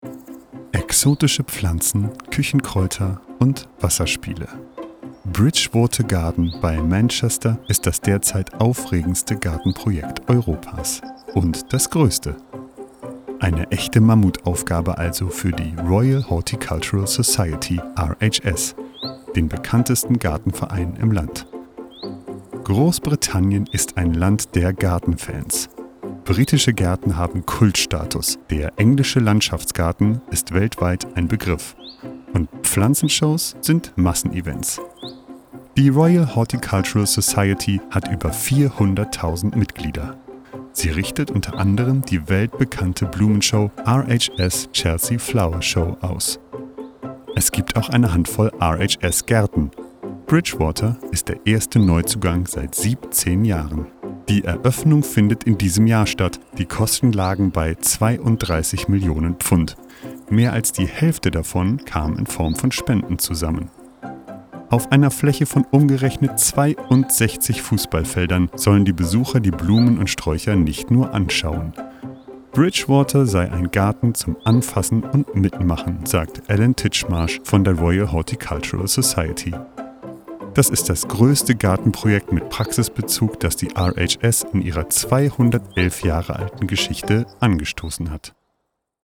dunkel, sonor, souverän, markant, sehr variabel
Alt (50-80)
Norddeutsch
Commercial (Werbung)